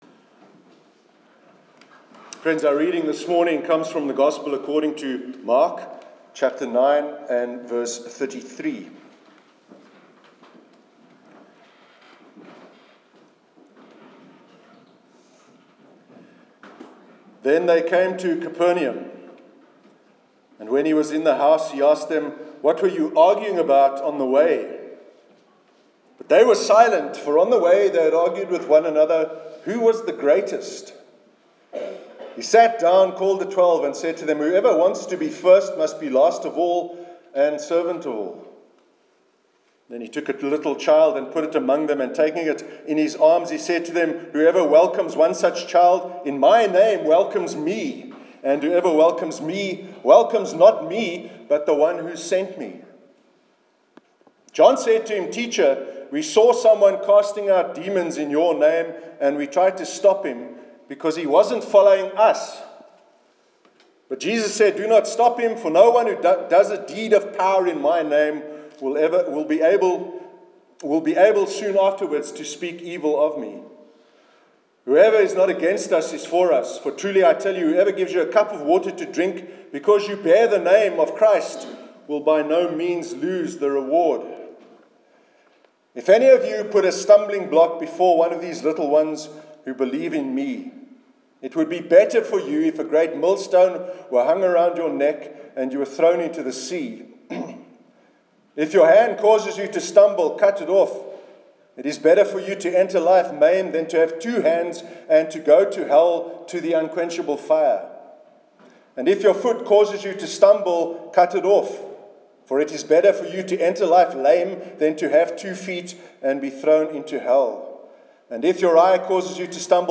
Sermon concluding “A Brief History of Hell”